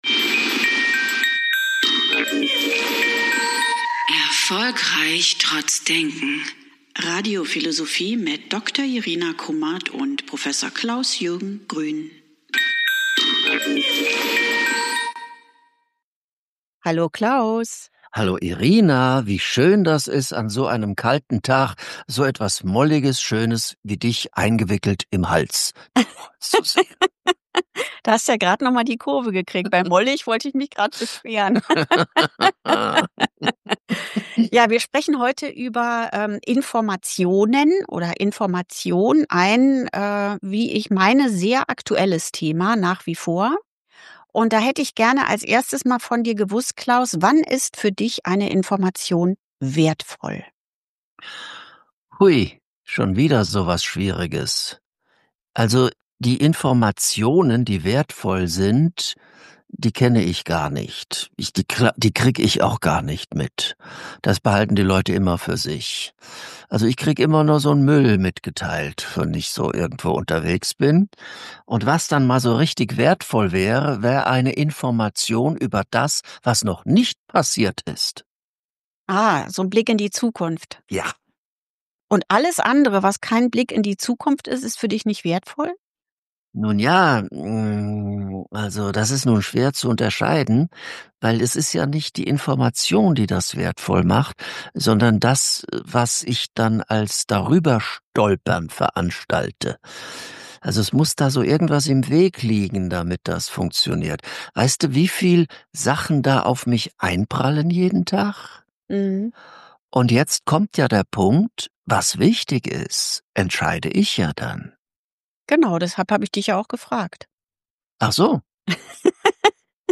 im Gespräch darüber, was eine Information sein und was sie auslösen kann.